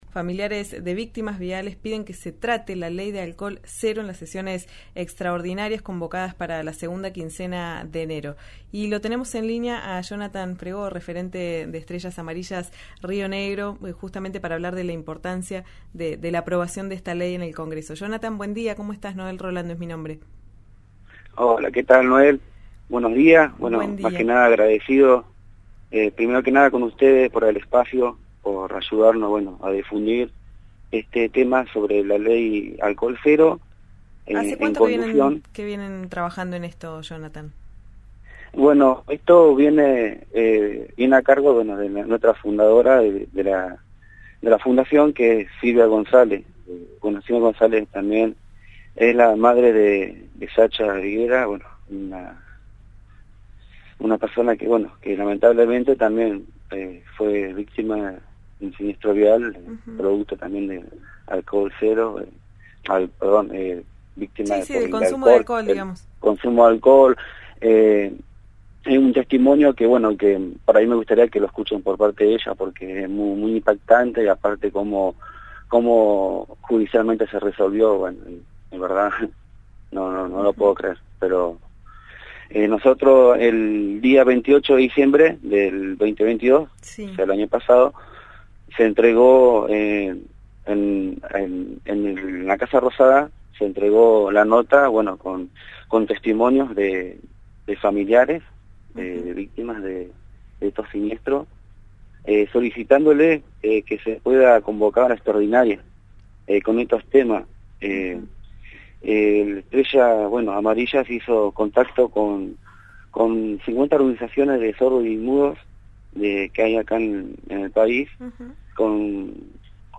dialogó con RÍO NEGRO RADIO y contó la importancia de la aprobación de esta ley.